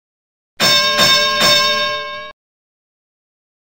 جلوه های صوتی
دانلود صدای زنگ شروع مسابقه بوکس 2 از ساعد نیوز با لینک مستقیم و کیفیت بالا